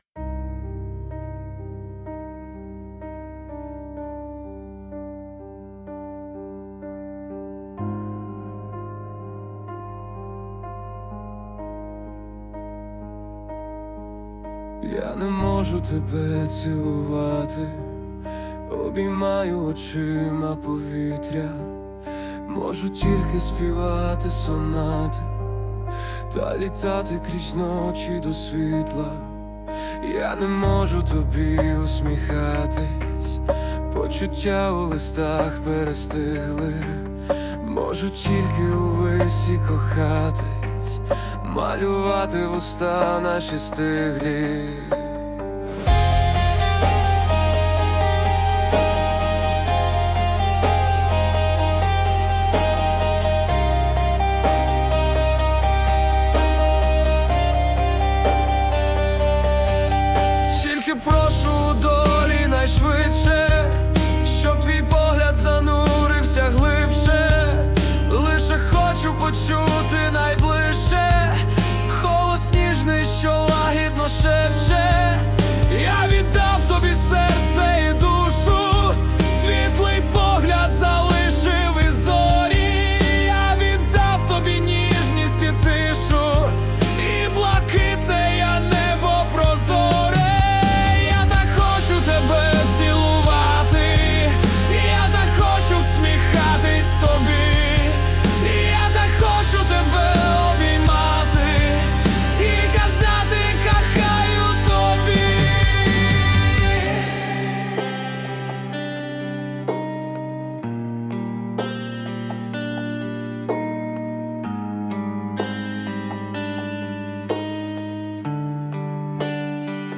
СТИЛЬОВІ ЖАНРИ: Ліричний
ВИД ТВОРУ: Пісня